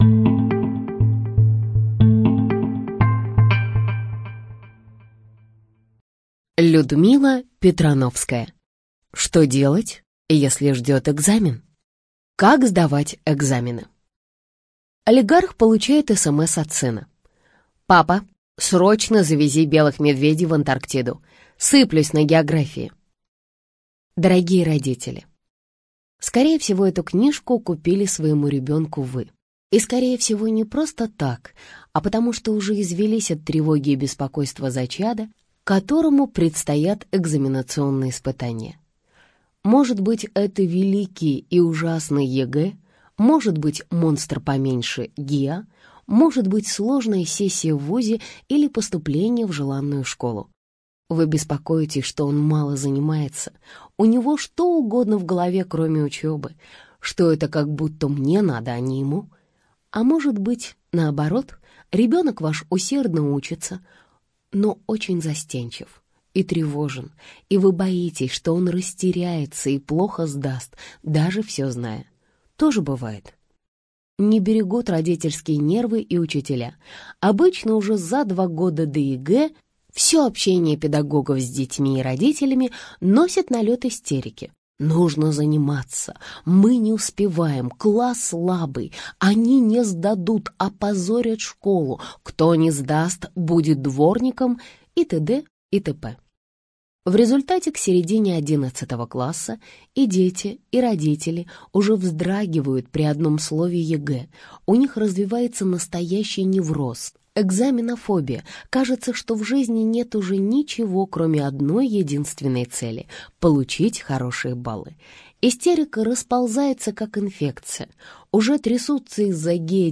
Аудиокнига Что делать, если ждет экзамен?